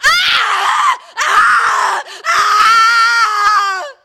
scream_woman_2.ogg